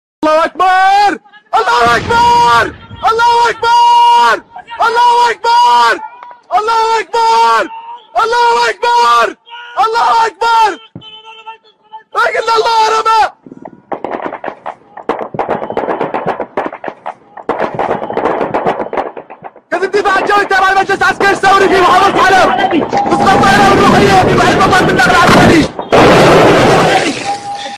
Звук, где мужчина кричит